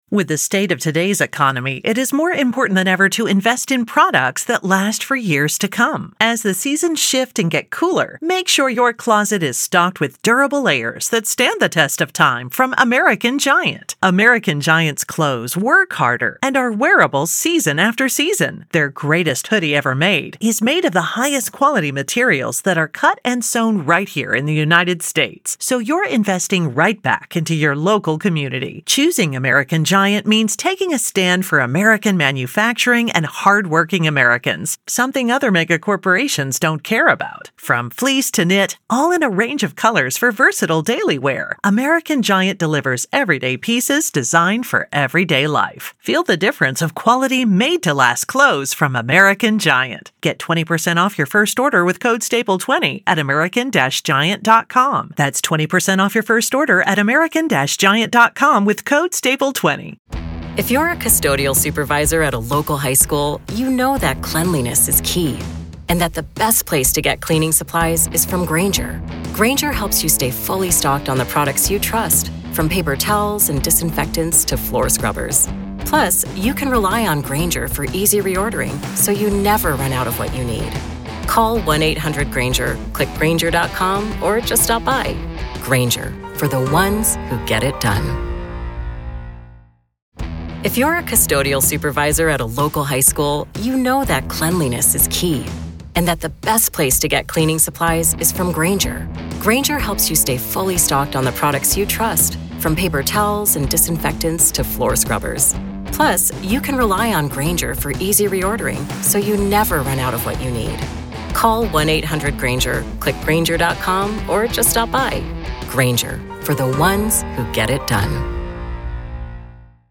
a renowned defense attorney